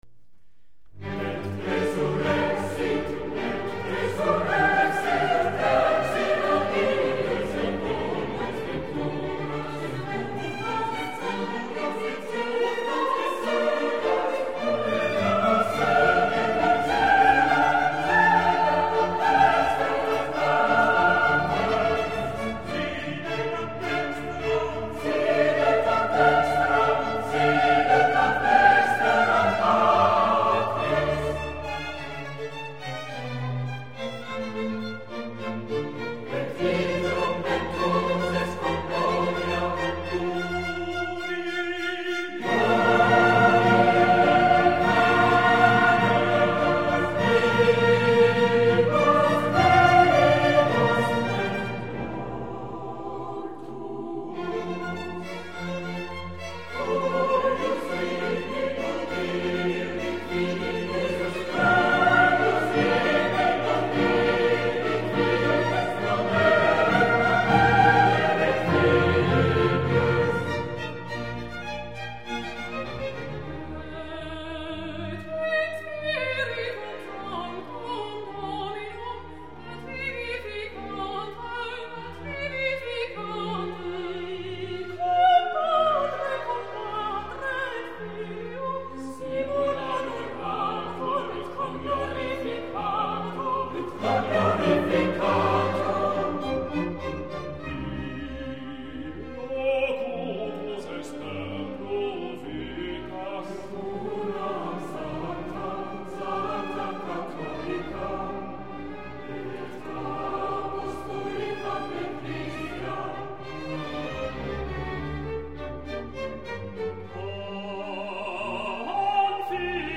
J. Haydn: Msza B dur Theresienmesse -- Credo, Et resurrexit...[.mp3]